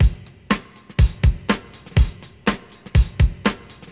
• 122 Bpm HQ Drum Loop A# Key.wav
Free drum loop sample - kick tuned to the A# note. Loudest frequency: 789Hz
122-bpm-hq-drum-loop-a-sharp-key-fpe.wav